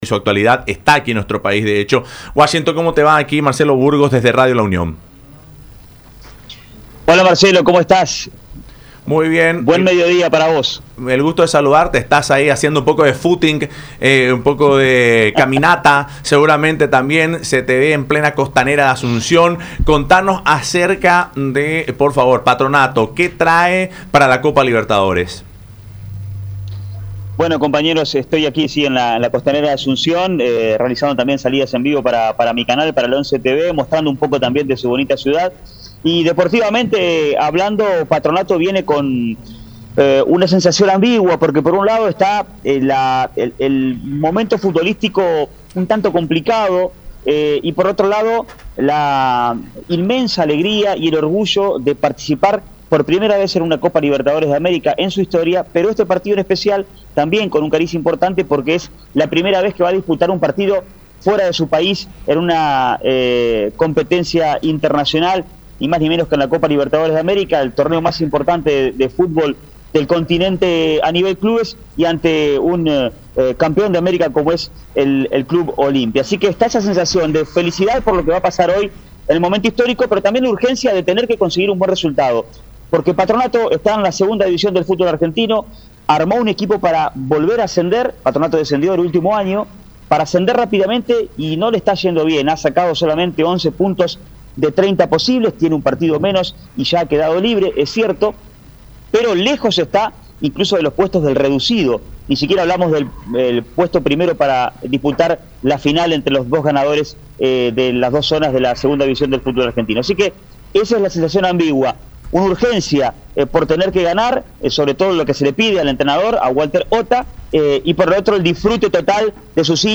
“El presente futbolístico no es bueno, crea muchas oportunidades de gol, pero erra mucho. Patronato está con la urgente necesidad de conseguir un buen resultado”, indicó el comunicador en contacto con Fútbol Club a través de Radio La Unión y Unión TV.